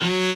admin-fishpot/b_cello1_v100l4o4fp.ogg